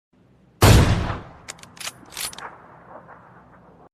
With its crisp tones and seamless integration
soothing melodies with subtle undertones